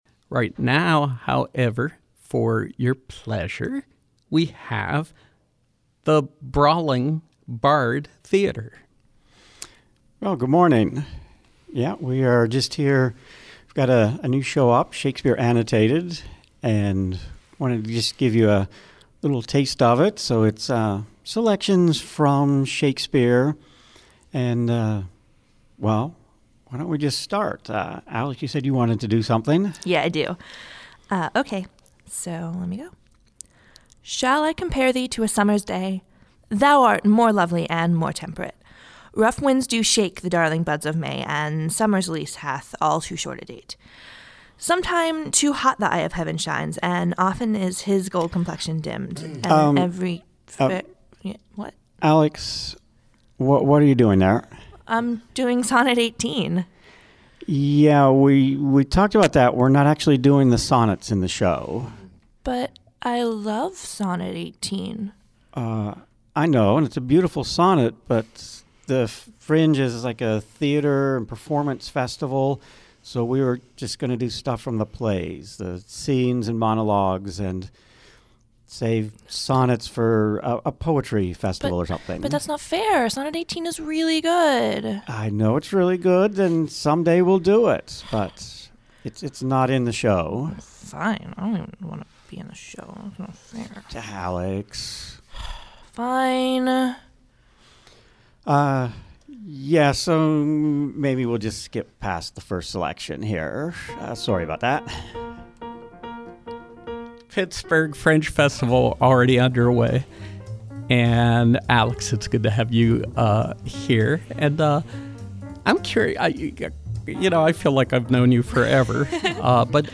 In Studio Pop-up: Brawling Bard Theater